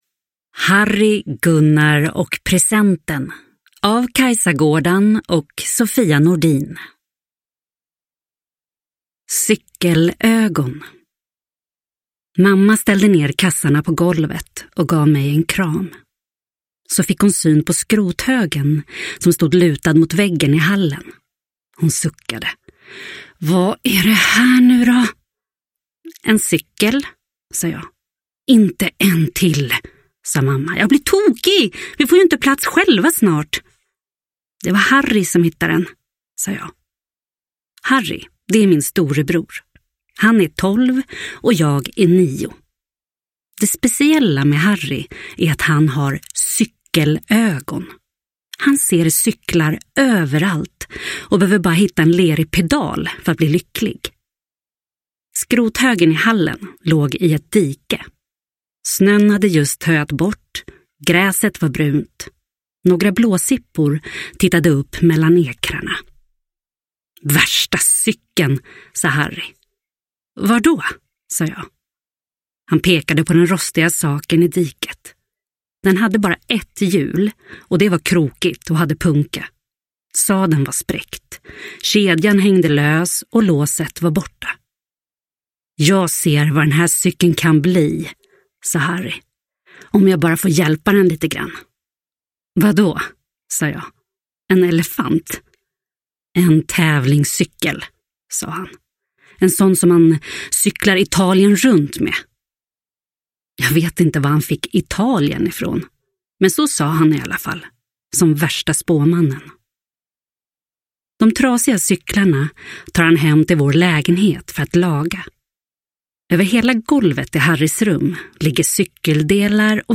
Harry, Gunnar och presenten – Ljudbok – Laddas ner